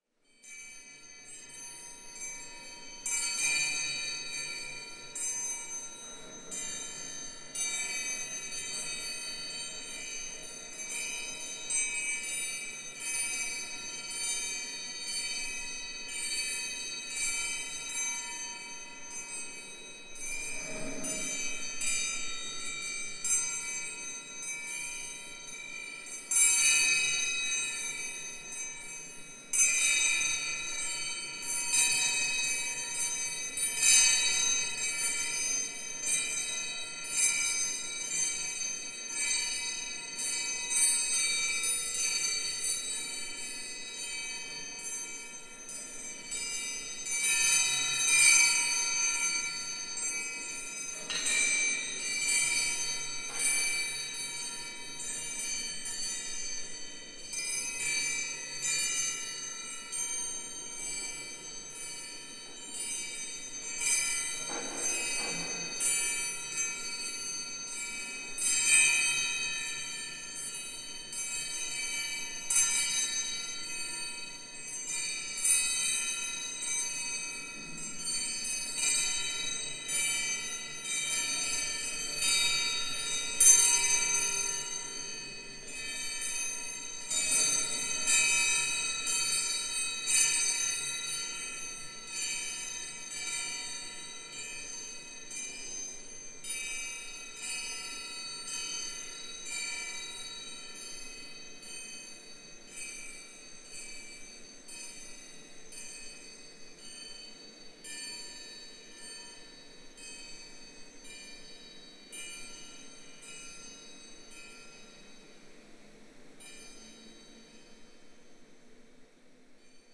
Metal-Tangs
ball chime clank clonk ding metal tang ting sound effect free sound royalty free Sound Effects